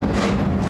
cp-anim6-slide-up.ogg